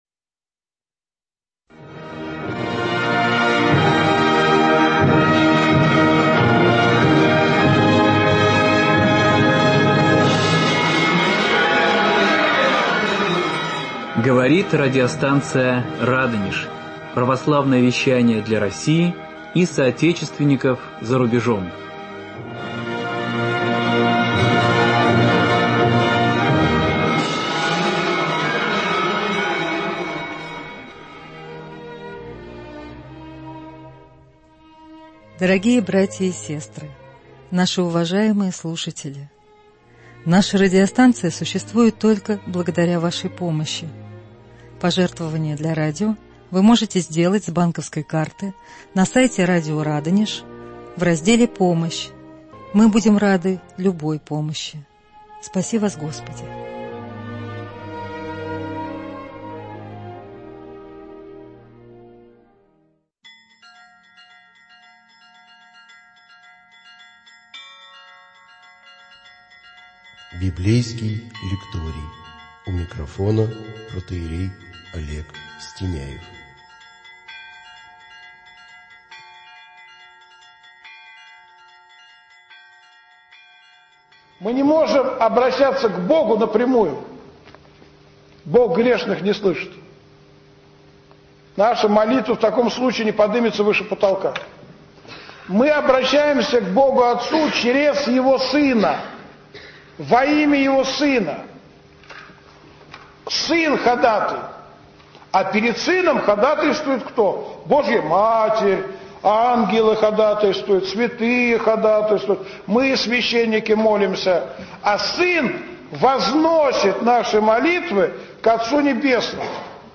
Беседы на книгу Откровение Иоанна Богослова.